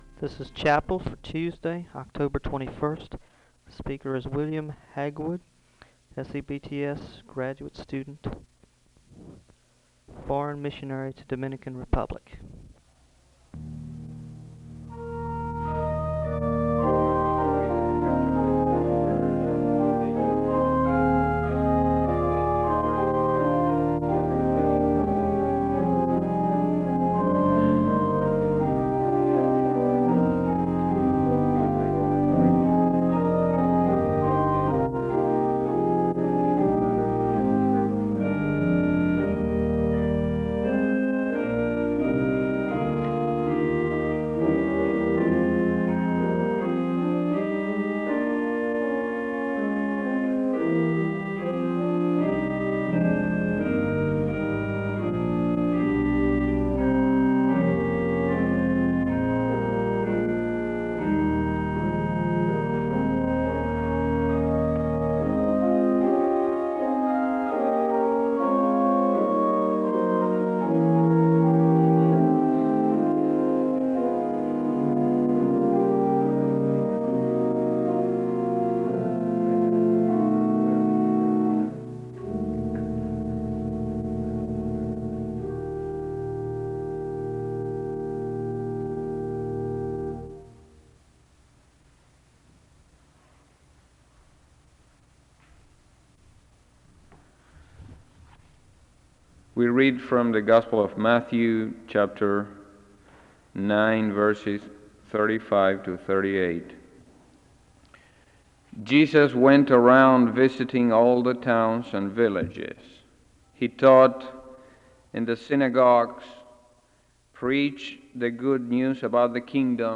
Disclaimer: Portions of this audio are inaudible.
The service begins with organ music (0:00-1:32). There is a Scripture reading from Matthew 9 (1:33-3:06). Prayer concerns are shared with the congregation and there is a moment of prayer (3:07-6:17).